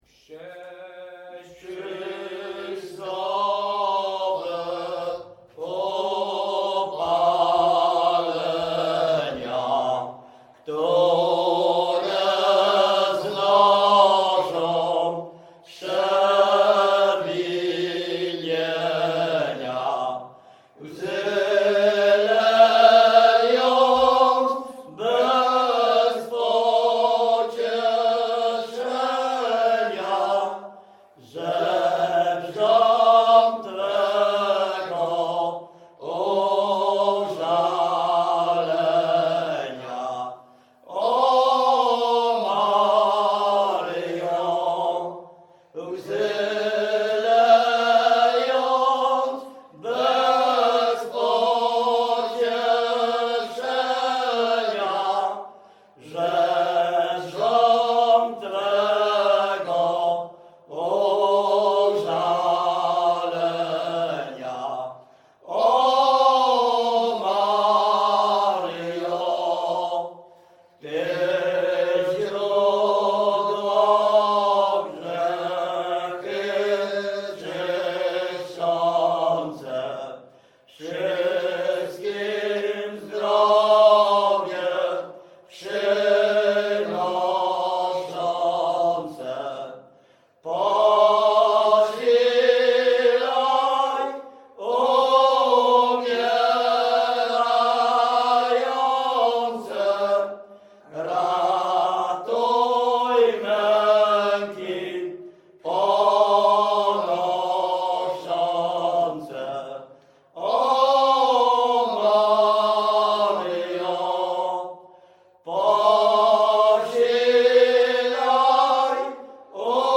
Pogrzebowa
Array nabożne katolickie maryjne pogrzebowe